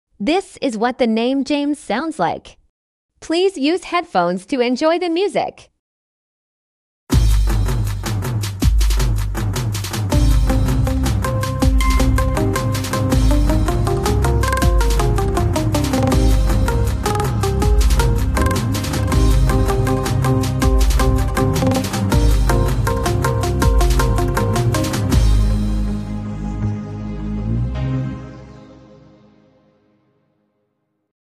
How the name James sounds like as midi art.